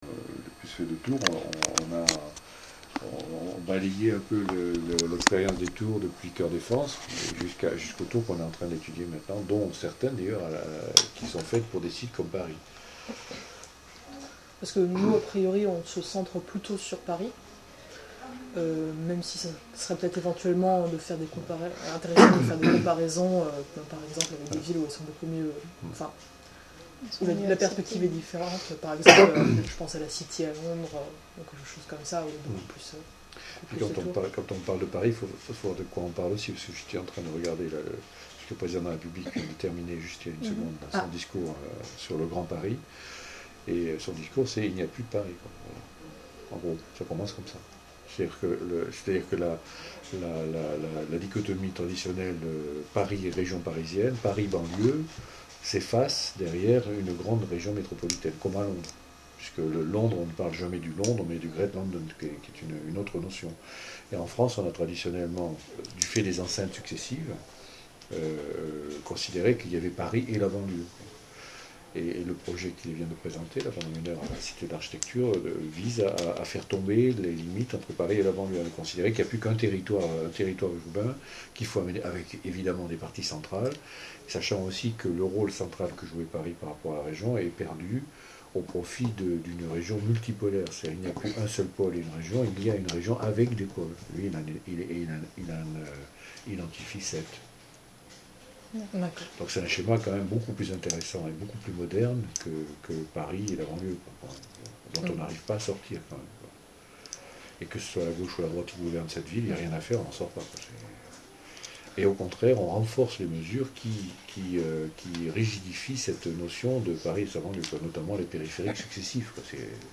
interview_jpv.mp3